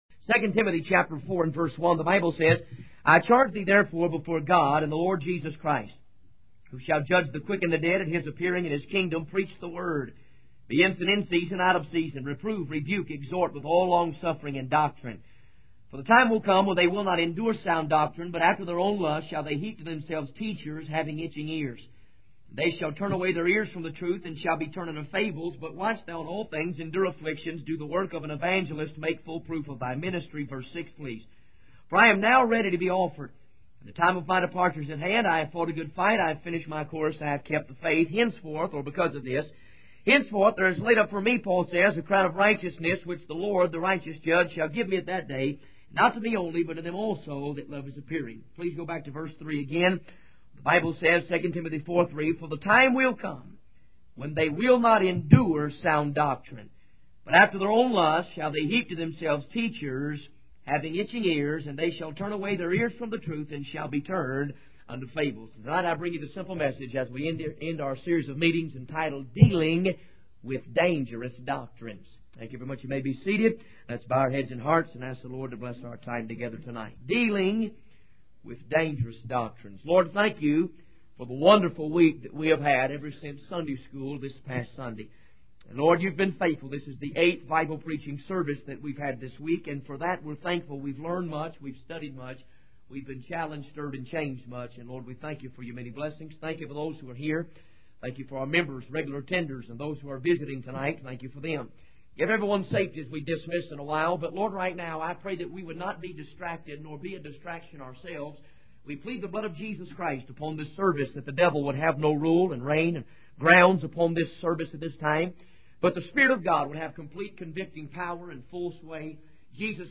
In this sermon, the preacher discusses the concept of being saved by the grace of God. He emphasizes that being saved is not dependent on spooky stories or emotional experiences, but rather on the conviction of the Holy Spirit and the recognition of one's need for Christ.